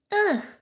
daisy_uh2.ogg